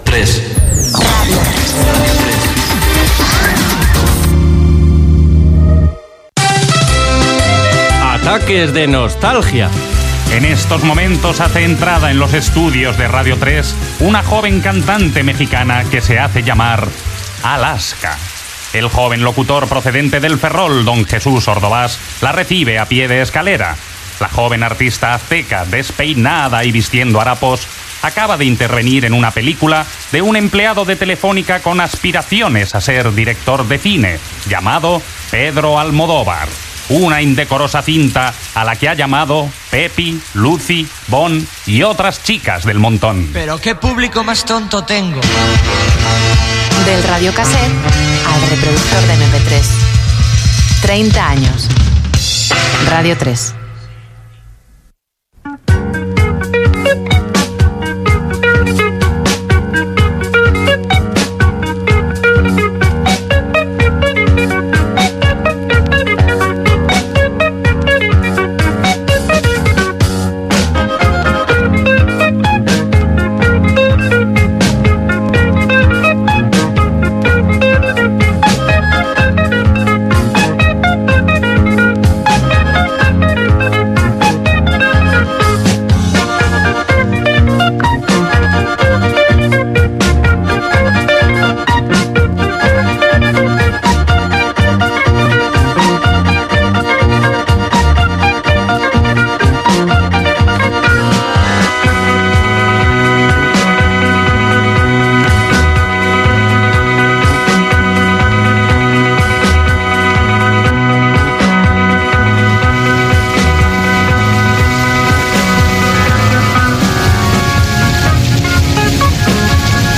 Indicatiu de la ràdio, careta del programa, presentació de l'hora 30 del programa
Gènere radiofònic Entreteniment